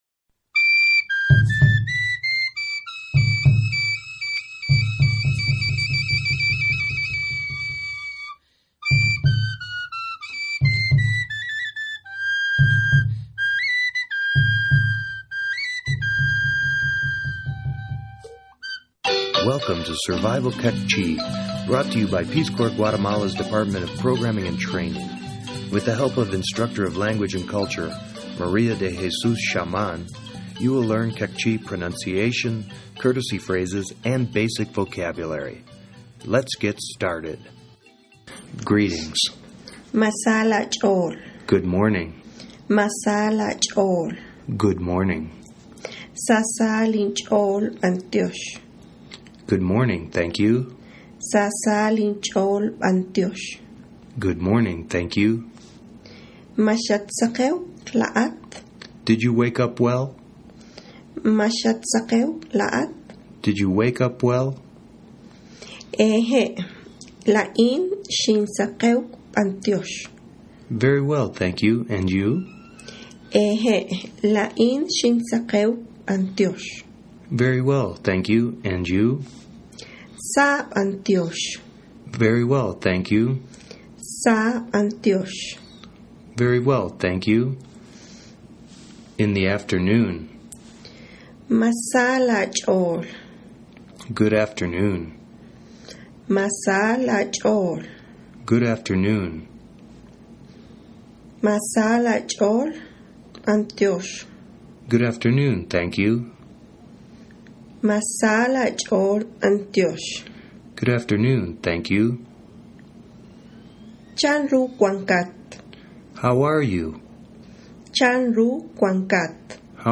Survival Queqchi - Lesson 01 - Greetings_.mp3